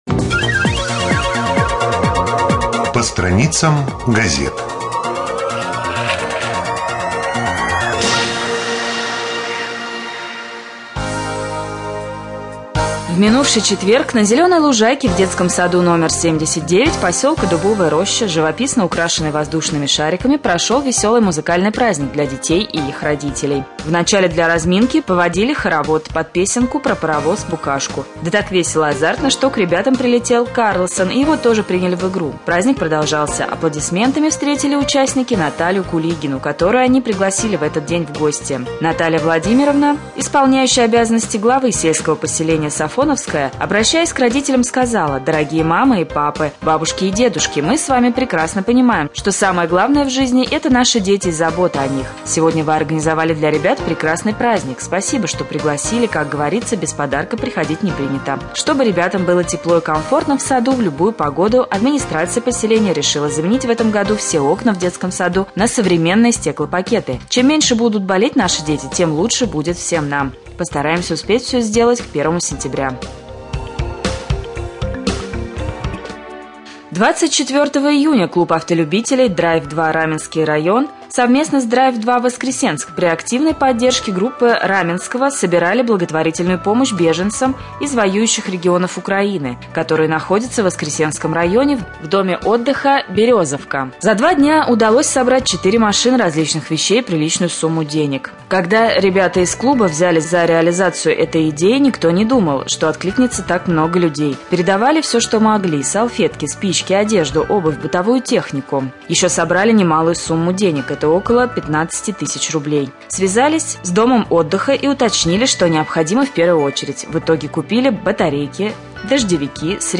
02.07.2014г. в эфире раменского радио - РамМедиа - Раменский муниципальный округ - Раменское
1.Новости.mp3